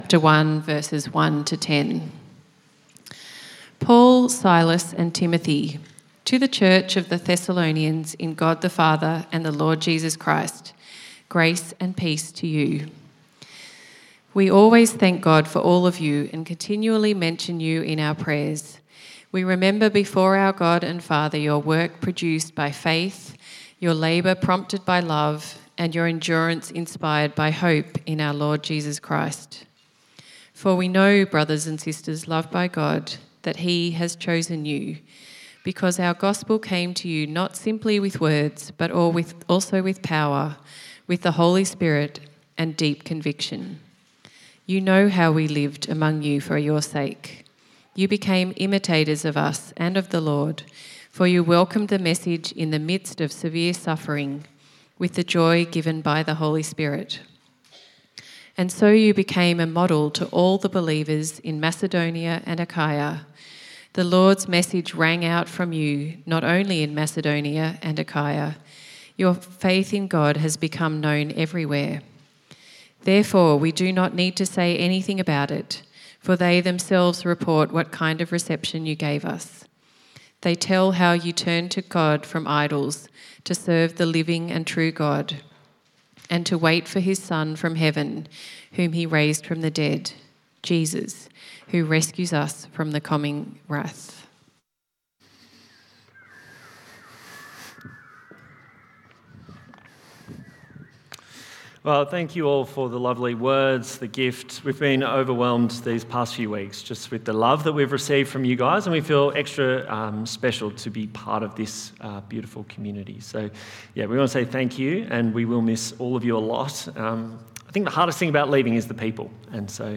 Service Type: 4PM